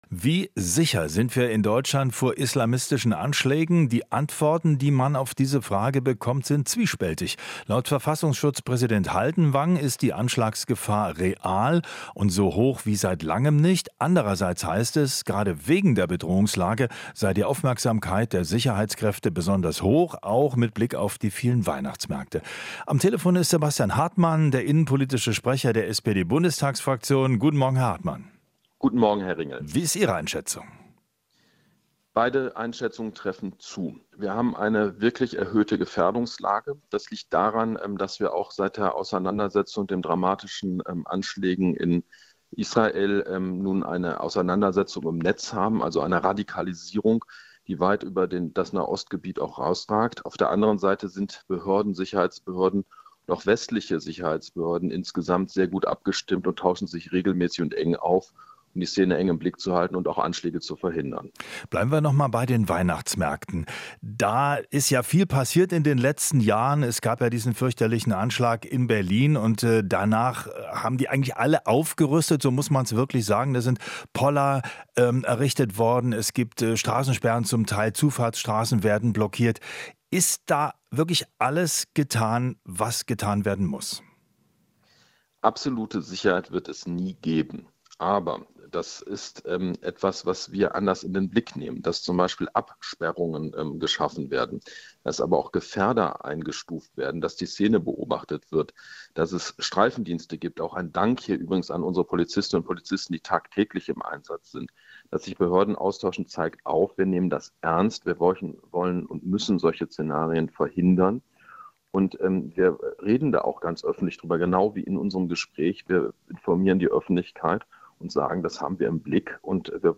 Interview - SPD-Innenexperte Hartmann: Terrorgefahr gestiegen